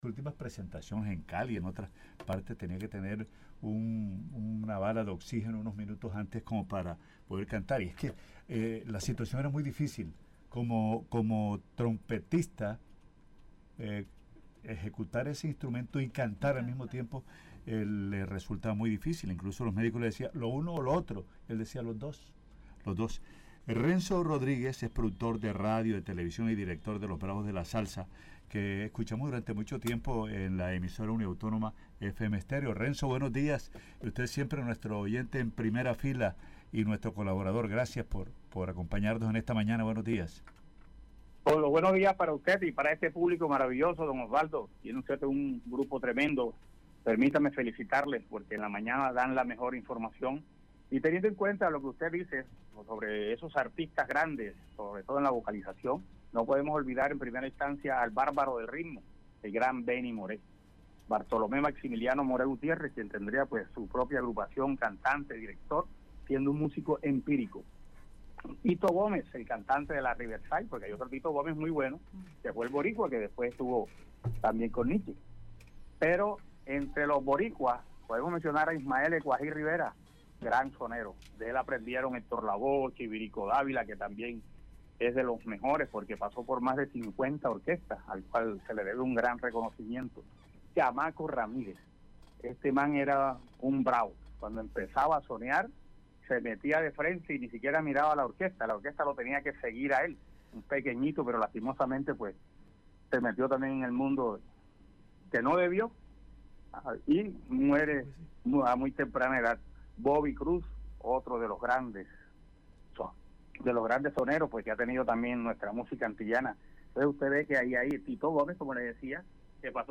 durante una entrevista en la que analizó el impacto del músico estadounidense de ascendencia puertorriqueña.